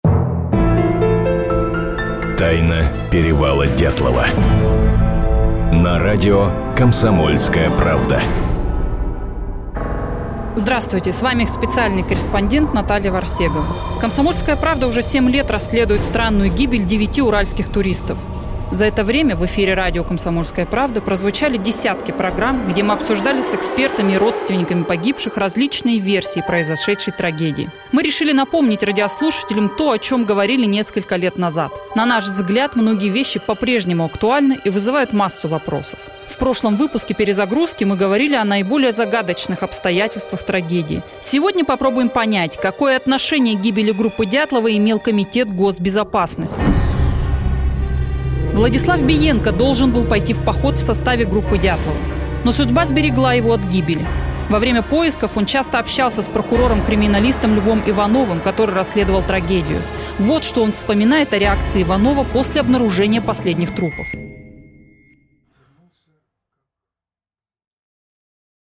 Аудиокнига Тайна перевала Дятлова: Перезагрузка, часть вторая. Итоги семилетнего расследования | Библиотека аудиокниг